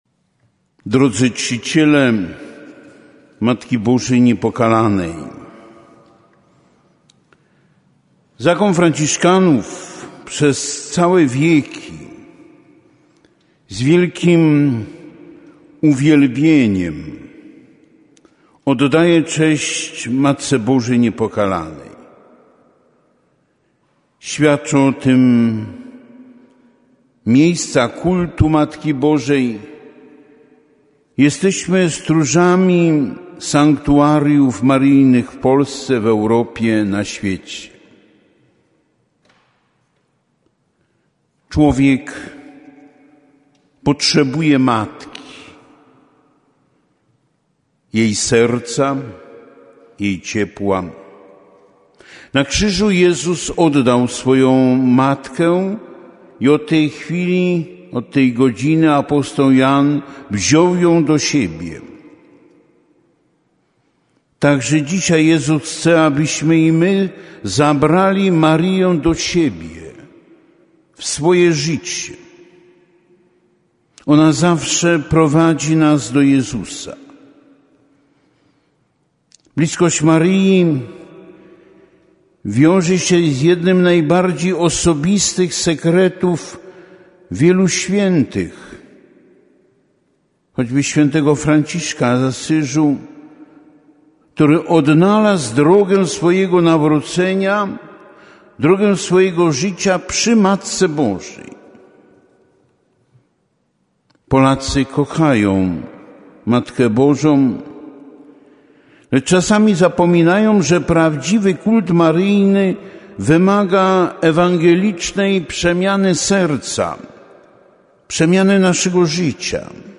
Czuwanie Maryjne – kościół oo. Franciszkanów w Przemyślu, 2 grudnia 2019 r.